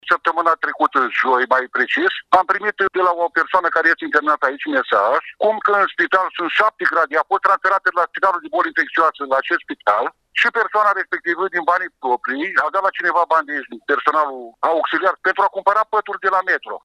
30-nov-ora-14-protestatar-1.mp3